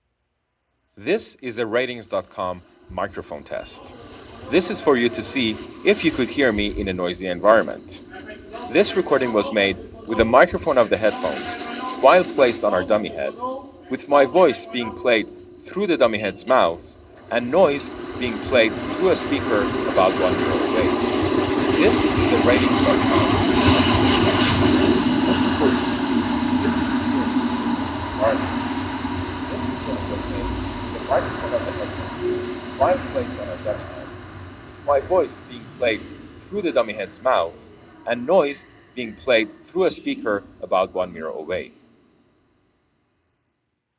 Speech + Subway Noise Audio Sample
As you'd expect, the integrated microphone doesn't perform as well as the boom microphone.
subway noise sample for comparison.
internal-mic-sam-subway.wav